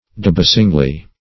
debasingly - definition of debasingly - synonyms, pronunciation, spelling from Free Dictionary Search Result for " debasingly" : The Collaborative International Dictionary of English v.0.48: Debasingly \De*bas"ing*ly\, adv. In a manner to debase.